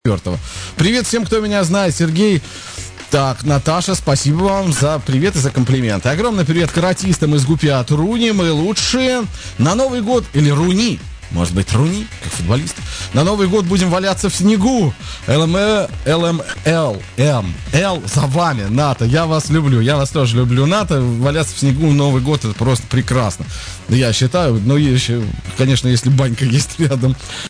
11.11.06г. Привет каратистам из МГУПИ по радио